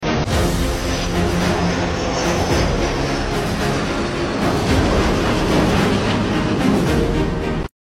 Pemandangan Dari Kg Melayu Subang Ketika Meksu Mendarat Selepas Sortie Raptai Penuh Merdeka Flypast 2025.